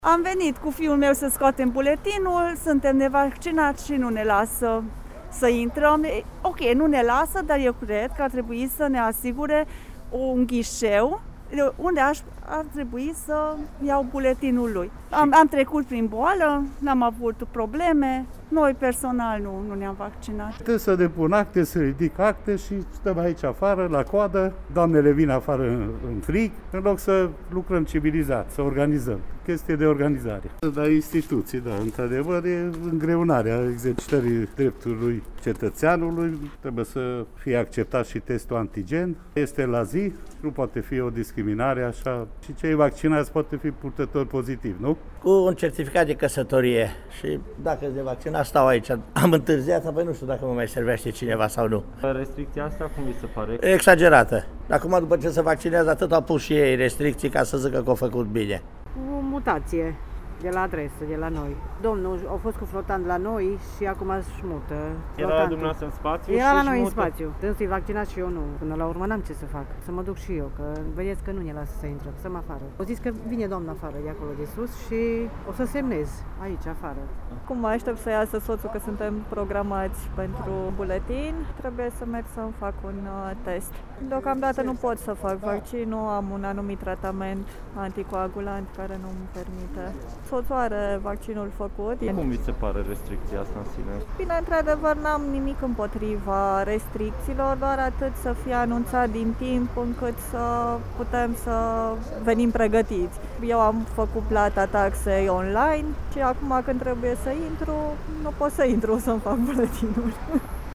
În general, persoanele nevaccinate cu care am discutat consideră restricțiile de acces în instituții publice exagerate, iar în timp ce unii sunt dispuși să se vaccineze pentru a nu mai întâlni opreliști, alții sunt de părere că nu asta este soluția la o problemă medicală: